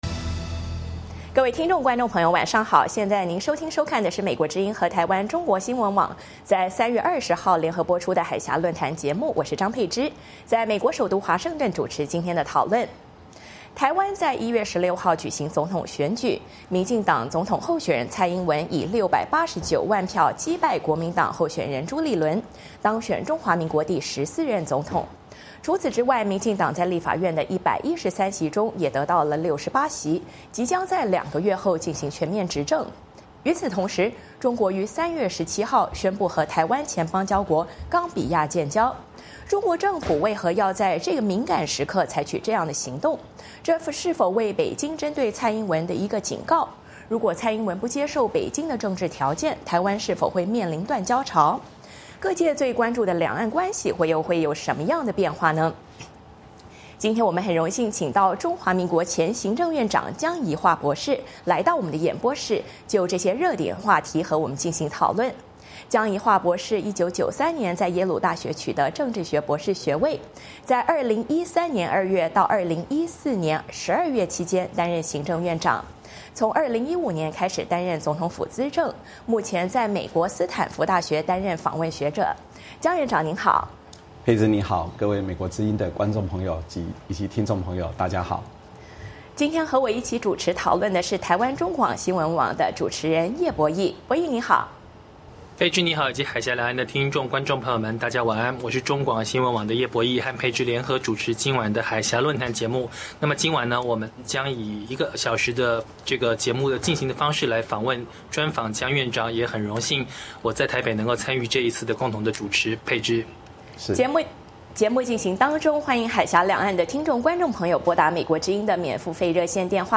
海峡论谈: 专访台湾前行政院长江宜桦谈蔡英文上任后两岸外交